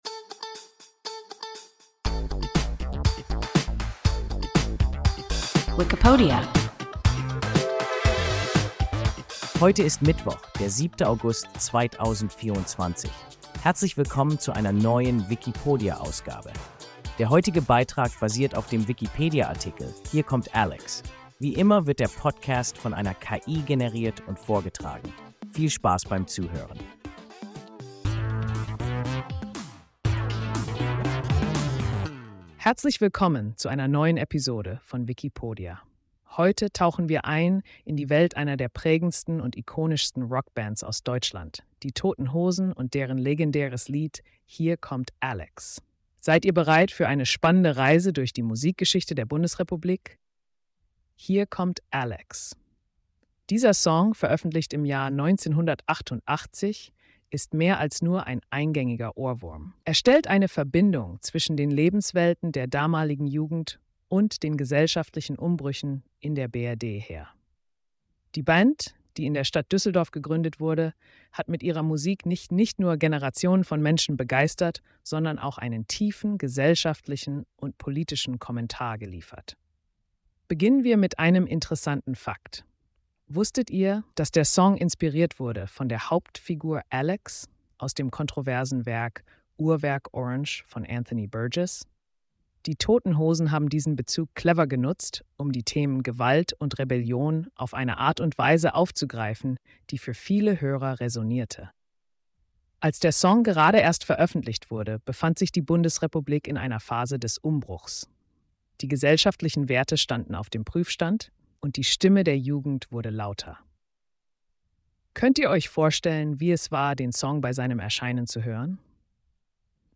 Hier kommt Alex – WIKIPODIA – ein KI Podcast